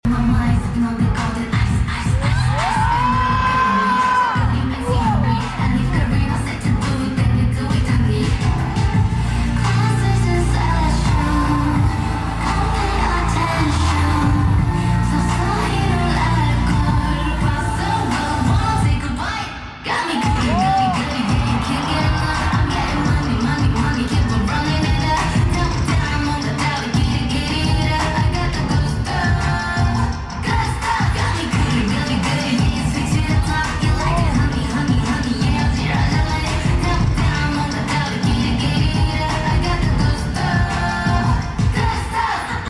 Fancam